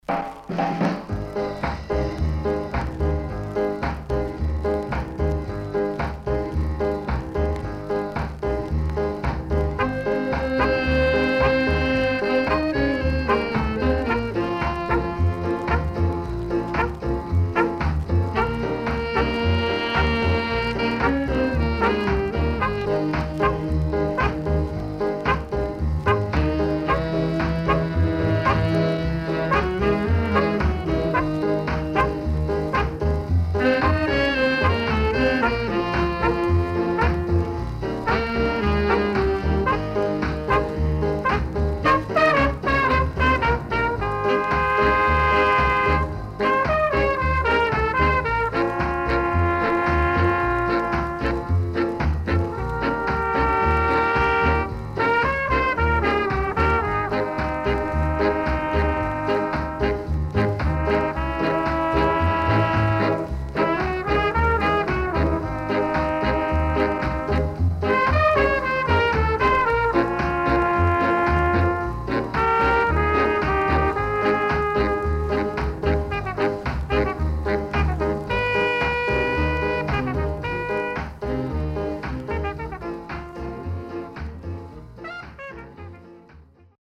W-Side Good Horn Inst
SIDE A:所々チリノイズ入ります。